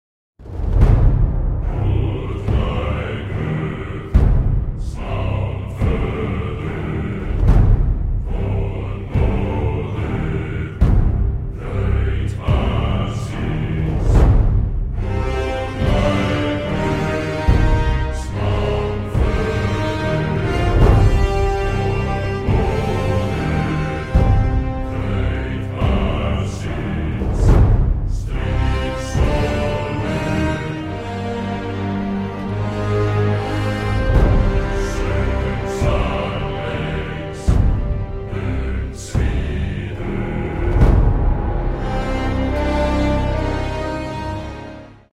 Video Game Soundtrack, Orchestral, Fantasy, Nordic Folk